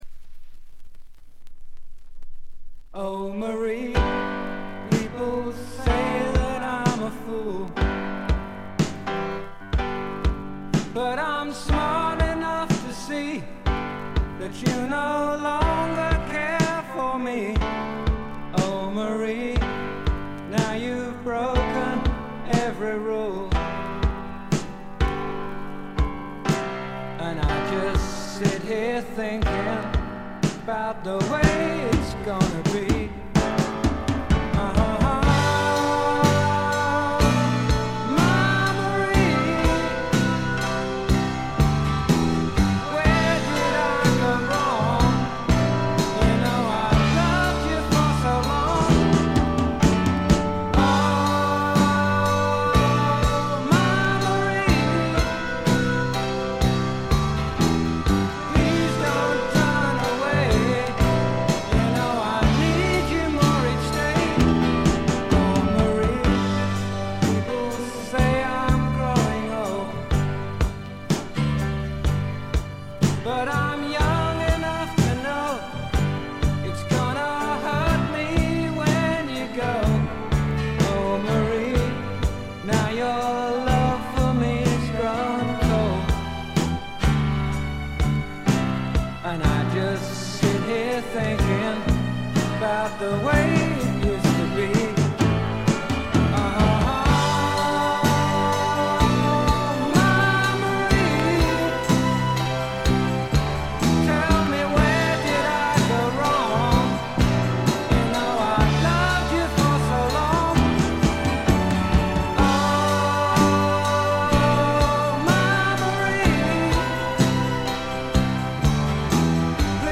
静音部で少軽いバックグラウンドノイズ程度。
試聴曲は現品からの取り込み音源です。